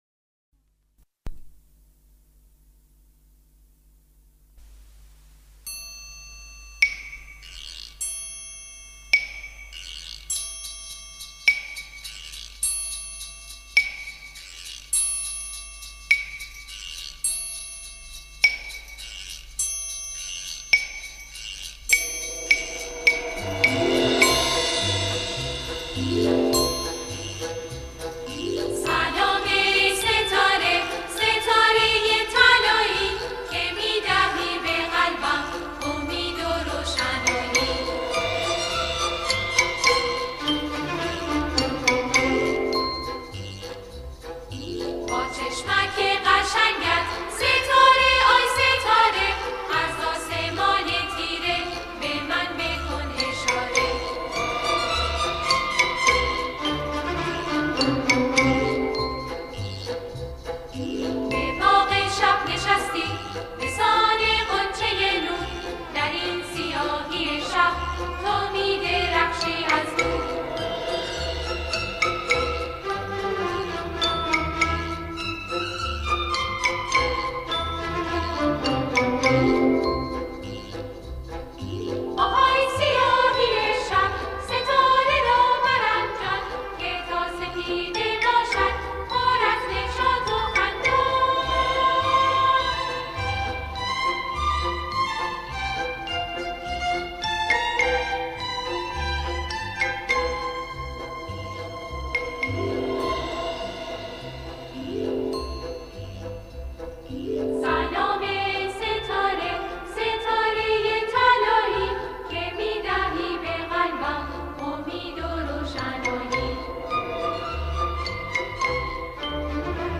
سرود های کودک